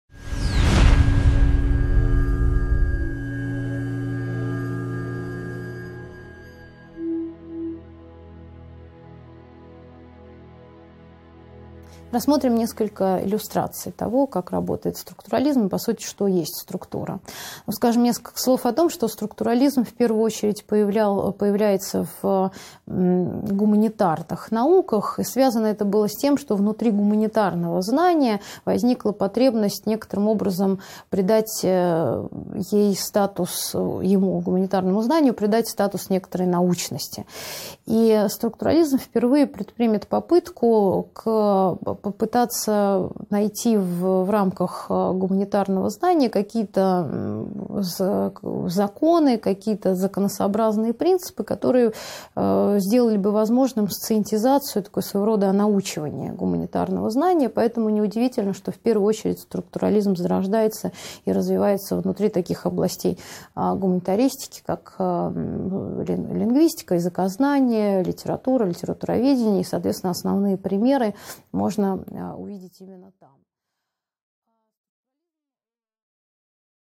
Аудиокнига 15.6 Структурализм (продолжение) | Библиотека аудиокниг